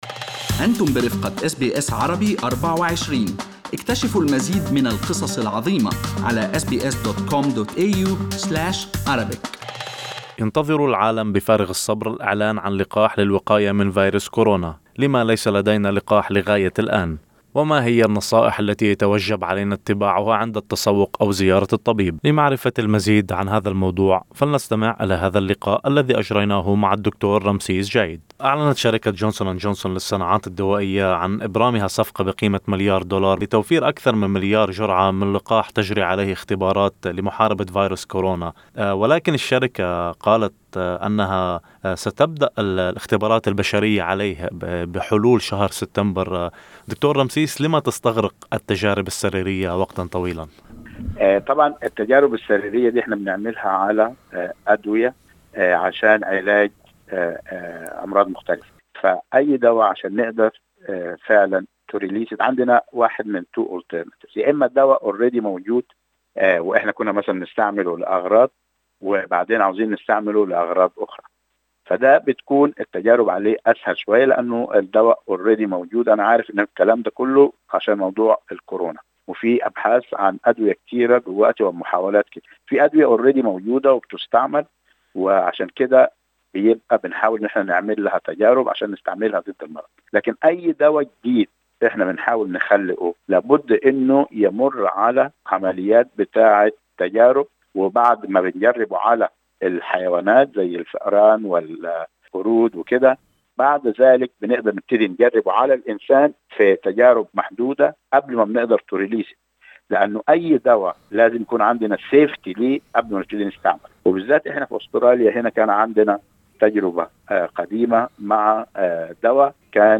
لم علينا انتظار التجارب السريرية للحصول على علاج لكورونا؟ هل لقاح الأنفلونزا فعال؟ وكيف يمكنك حماية نفسك من العدوى عند التسوق؟ والمزيد في هذا اللقاء مع طبيب الصحة العامة